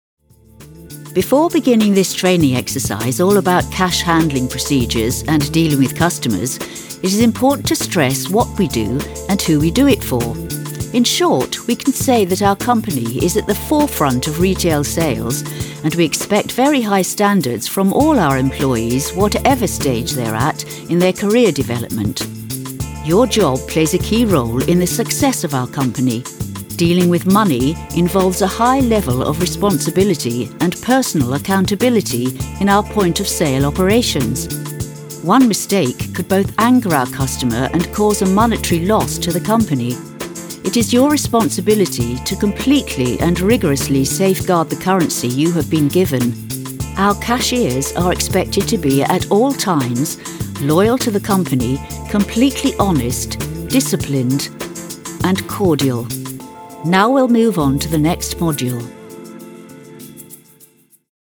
Female
English (British)
Adult (30-50), Older Sound (50+)
E-Learning